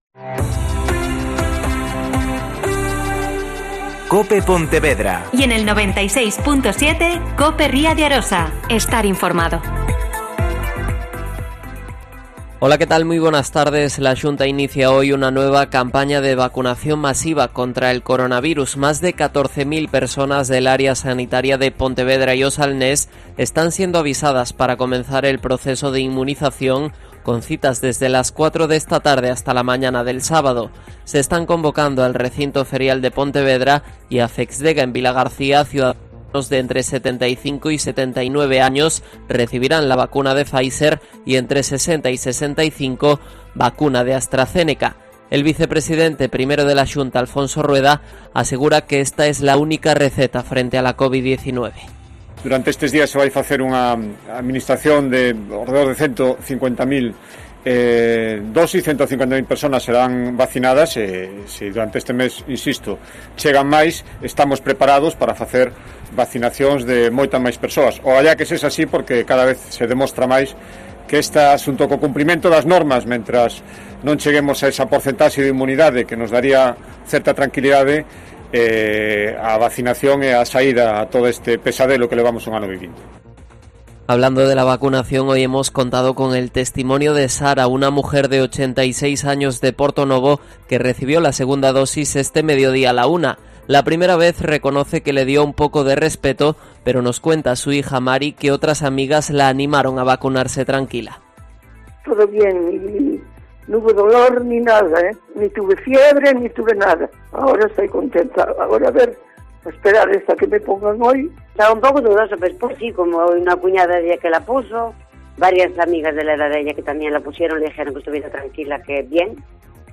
Mediodía COPE Pontevedra y COPE Ría de Arosa (Informativo 14,20h)